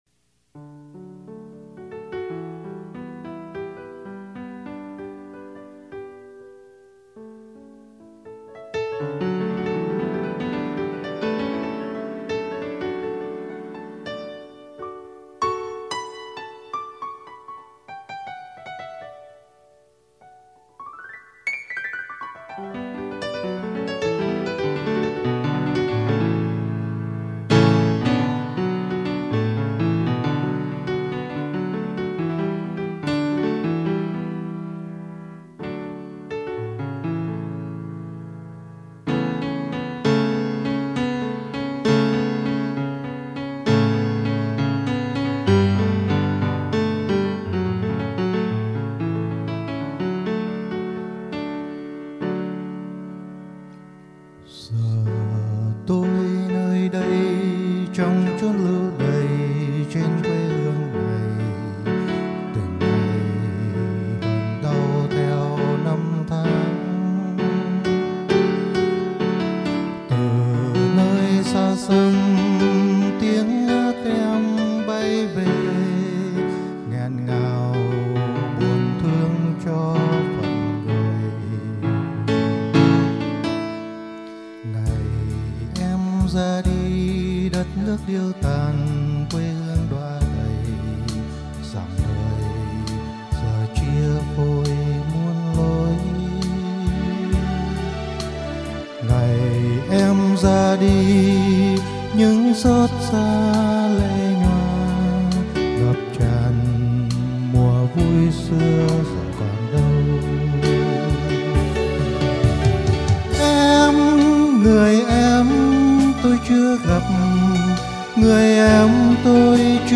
Thú thật ngày đó tôi chưa thấm thía hết cái đẹp của ca từ, mà sự cảm nhận qua âm điệu như vô tình chạm vào vô thức, mặc nhiên giọng hát và tiếng đàn của anh dù là hát cho riêng anh nhưng điều anh không ngờ là tác dụng của nó phần nào giúp cho các bạn đồng tù xua tan đi những cơn ác mộng trong giấc ngủ hằng đêm.